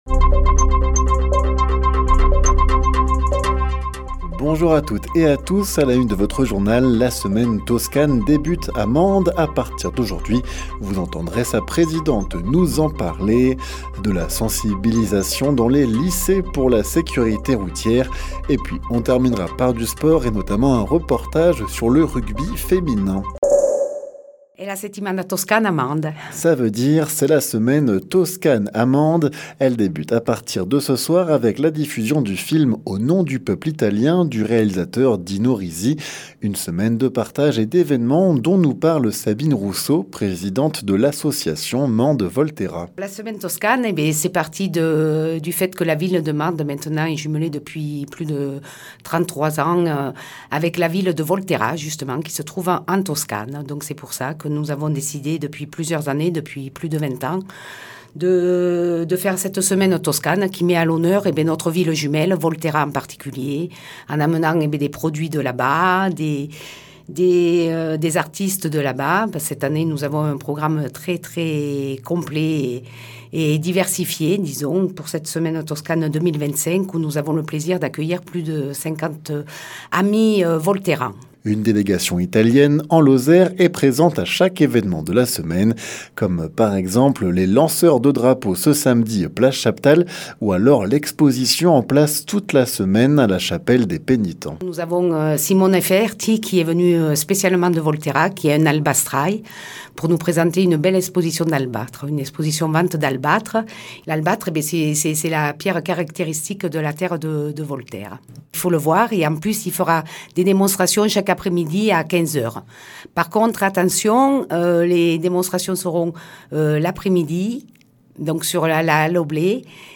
Le journal sur 48FM